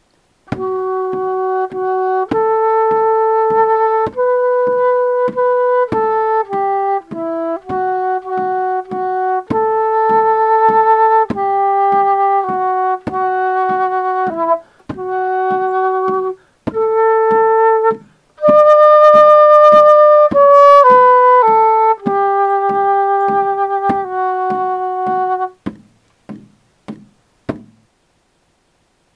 frigga_chant.mp3